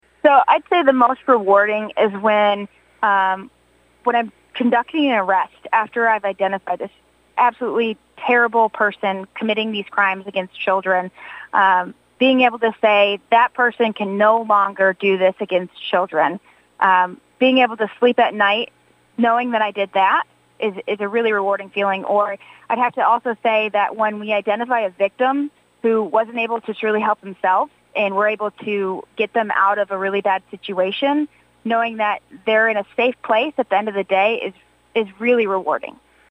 the Cromwell News Team held a phone interview